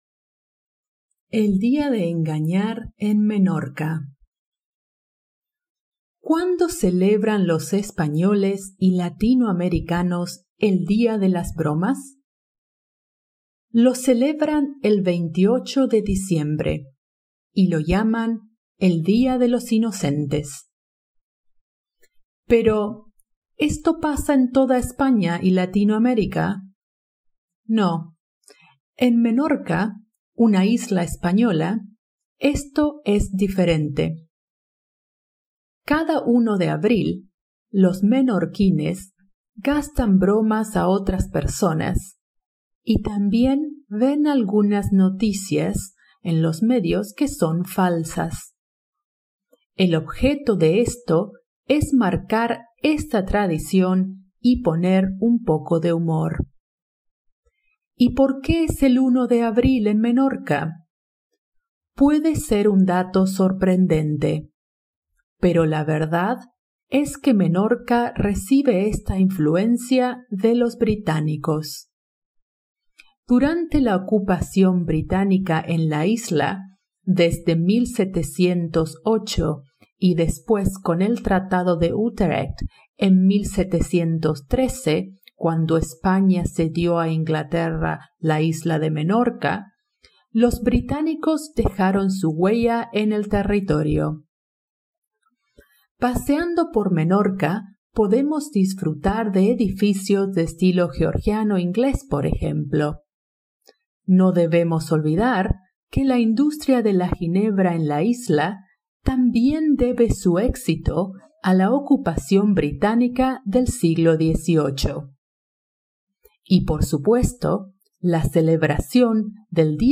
Spanish online reading and listening practice – level A2
using her native Argentinian accent